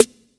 WSAG_SNR.wav